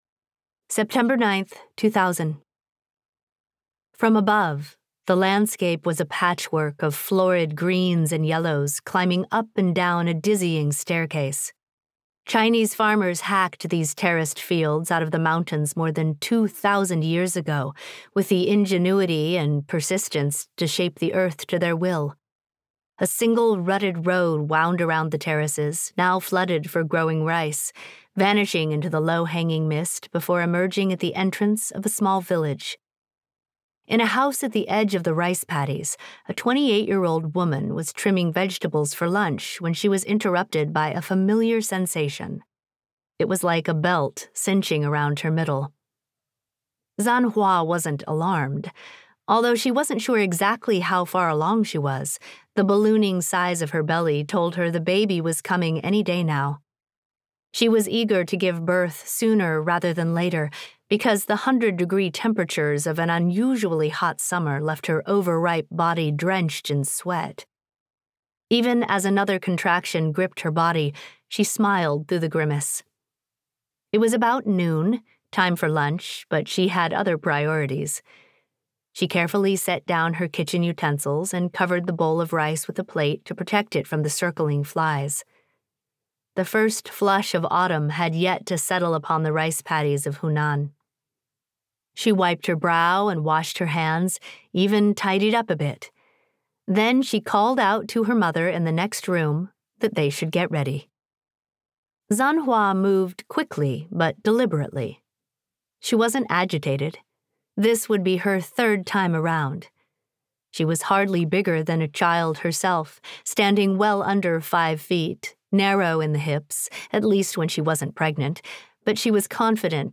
Audiobook sample
Daughters-of-the-Bamboo-Grove-Audio-Sample.mp3